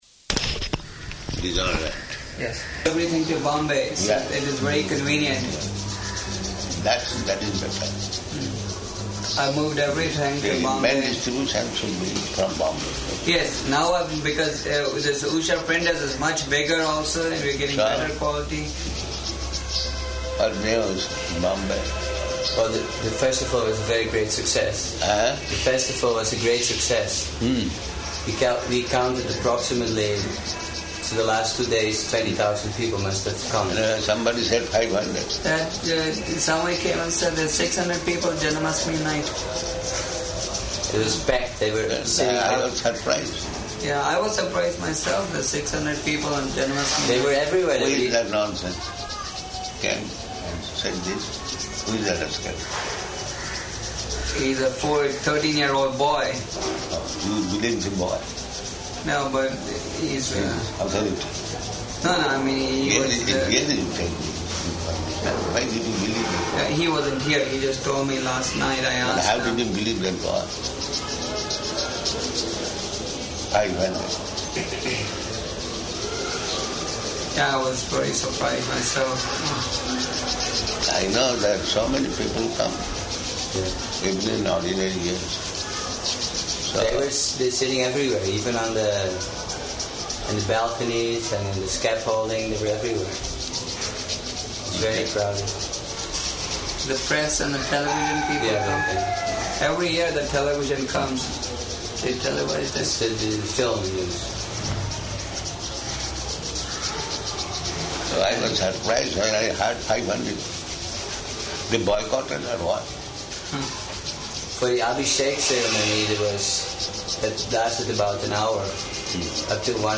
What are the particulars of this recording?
-- Type: Conversation Dated: August 20th 1976 Location: Hyderabad Audio file